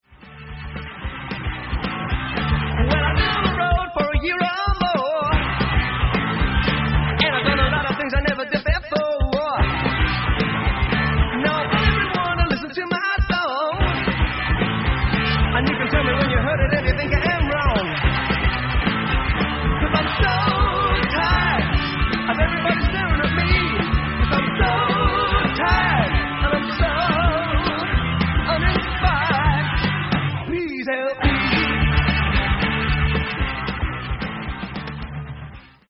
Musicland / Munich, Germany
Organ, Guitars & Vocals
Drums